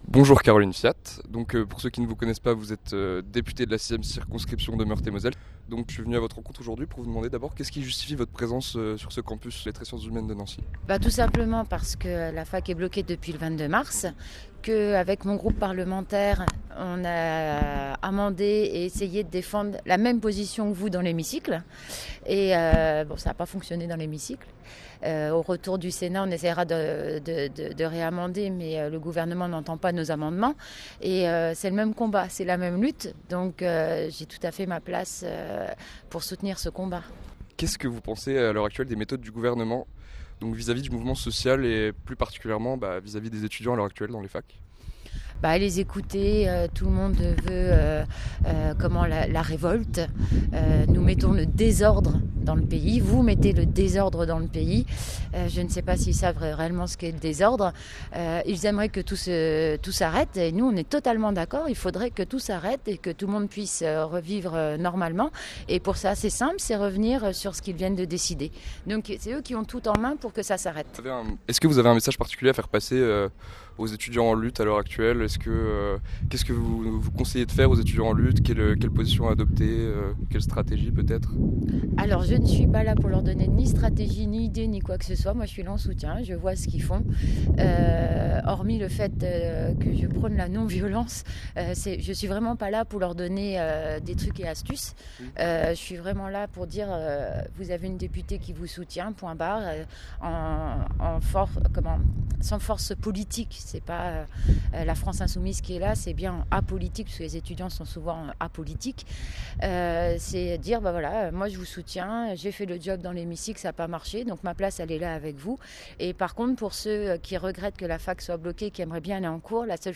Caroline Fiat : Interview d’une députée engagée aux côtés des étudiants
interview-caroline-fiat-v2.wav